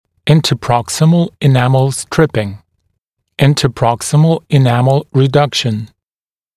[ˌɪ n təˈprɔk s ɪməl ɪ’ n æml ‘ s trɪpɪŋ] [ˌинтэ’проксимэл и’нэмл ‘стрипин]